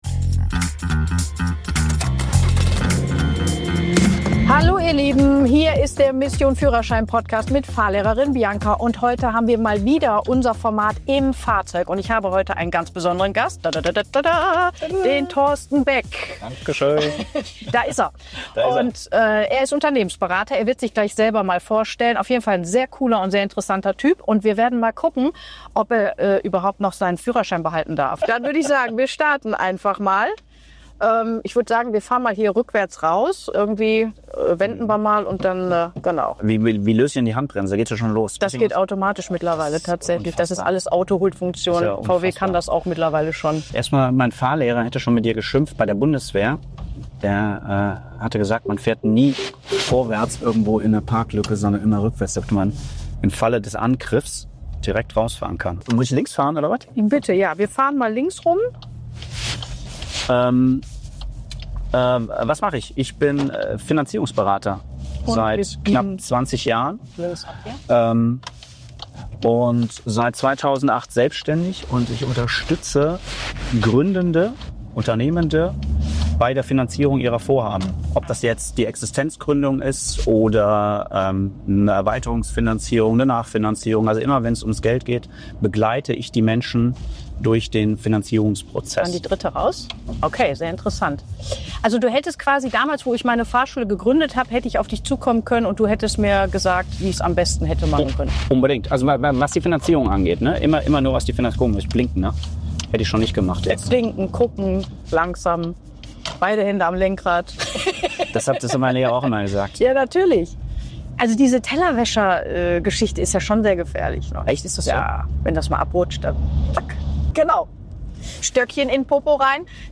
Wir fahren gemeinsam durch die Stadt, plaudern über seine Fahrschulzeit, das heutige Fahrverhalten und wie es ist, wenn man als Vater und Unternehmer auf den Beifahrersitz wechselt.